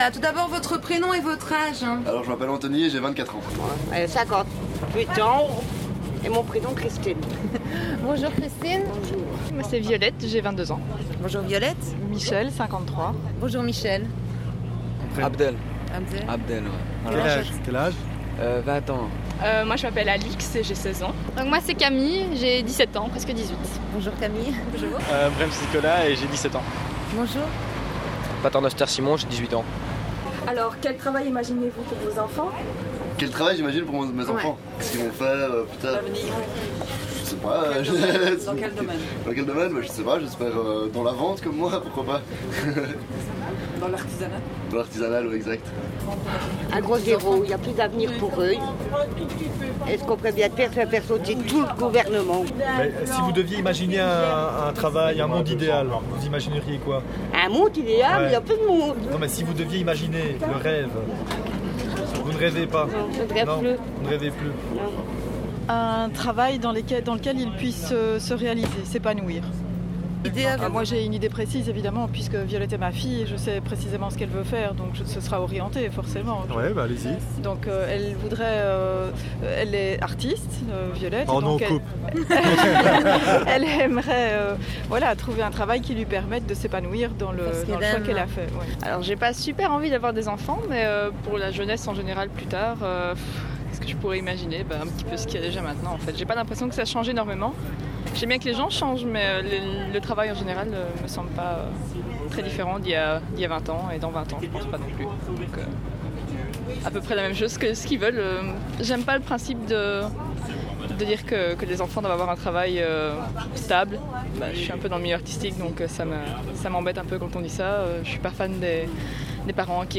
Le jeudi 12 octobre c’était les portes ouvertes chez notre partenaire, le Gsara de Charleroi, nous sommes allés en rue récolter des sons autour de la notion de « Travail-Emploi » que nous avons ensuite monté pendant l’atelier participatif proposé par le Gsara.
Micro-trottoir 1
Micro-trottoir-1.mp3